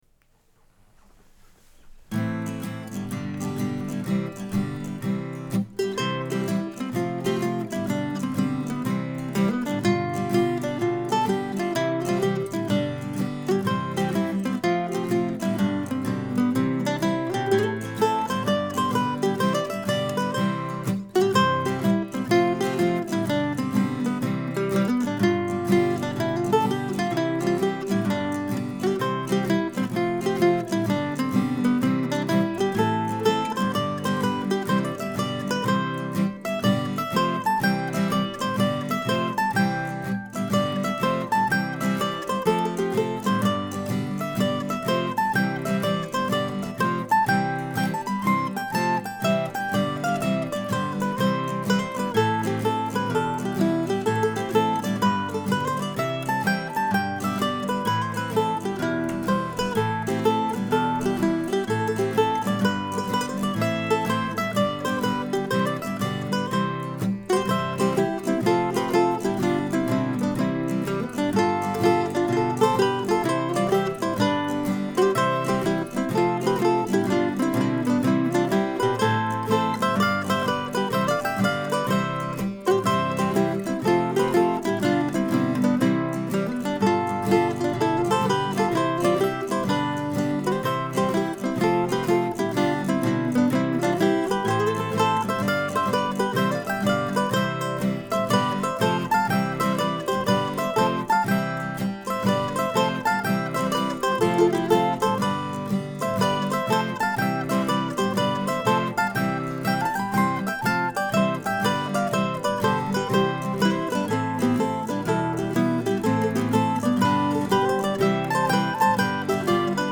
This week's new tune is a hornpipe in C, though it could be thought of as a schottische too I suppose.
A friendly melody with a simple harmony part.